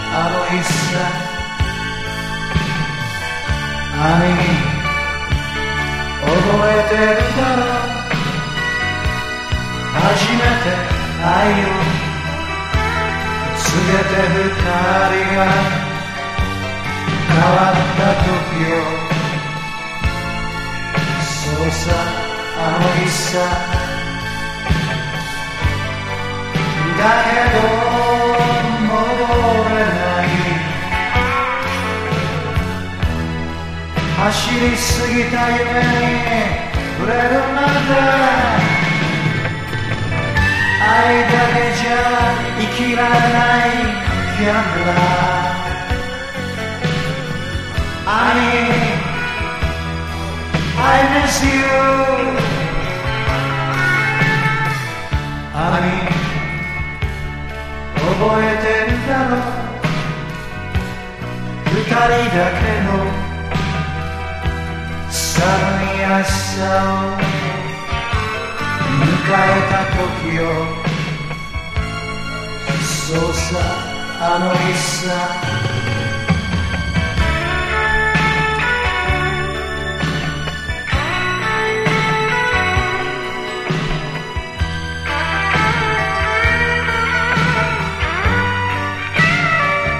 # 60-80’S ROCK